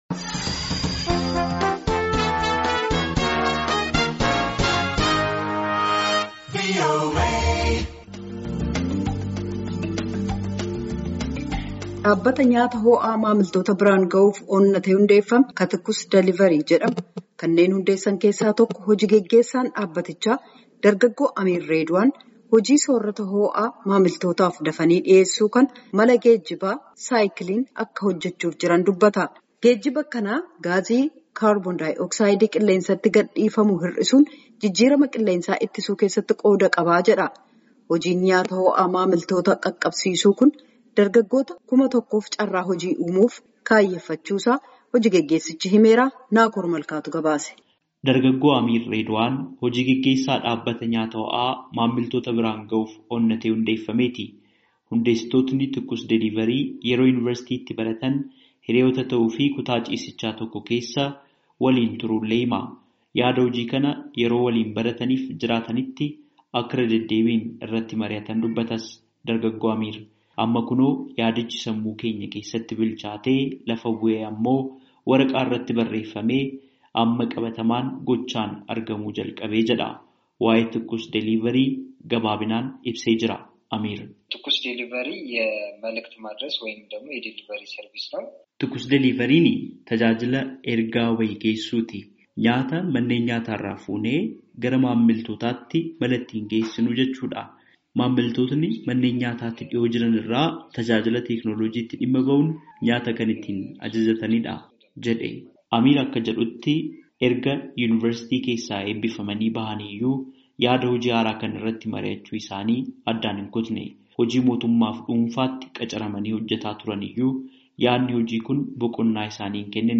Gabaasaa guutuu caqasaa